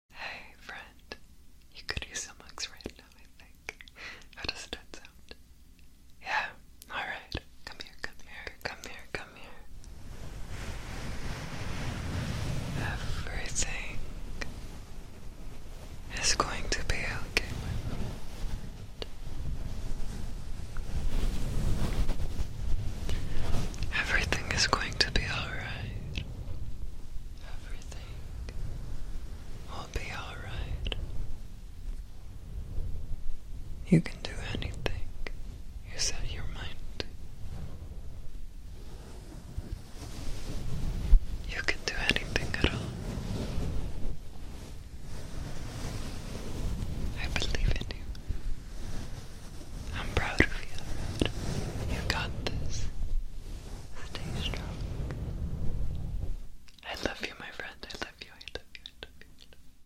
some wholesome asmr hugs❤ sound effects free download